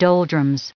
added pronounciation and merriam webster audio
705_doldrums.ogg